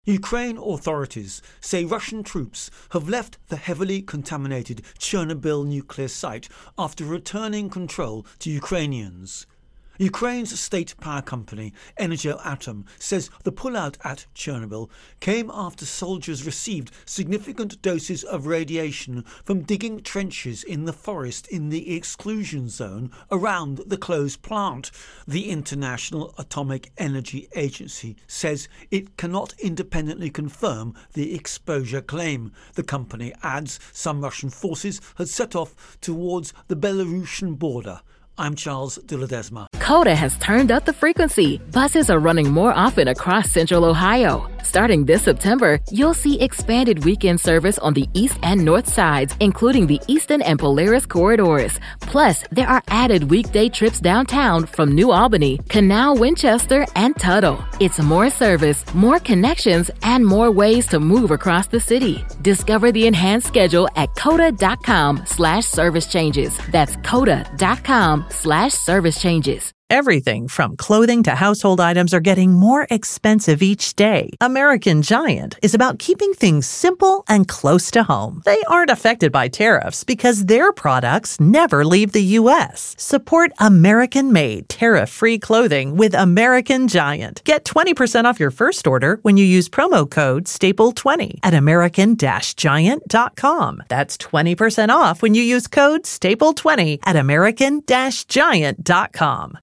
Russia Ukraine War Chernobyl Intro and Voicer